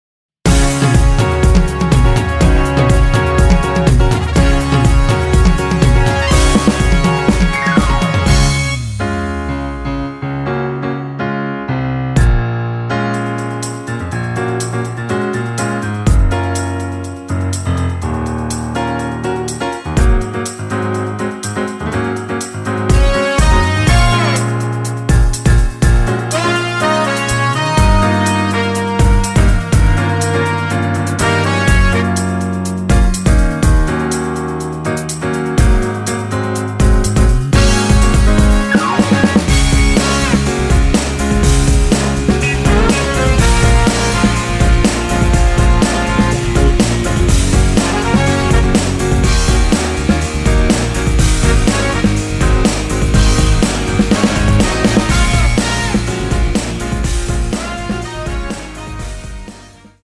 원키에서(-1) 내린 MR 입니다.(미리듣기 참조)
Db
앞부분30초, 뒷부분30초씩 편집해서 올려 드리고 있습니다.
곡명 옆 (-1)은 반음 내림, (+1)은 반음 올림 입니다.